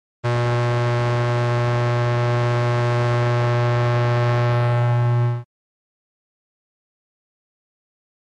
Electrical Hum With Buzz.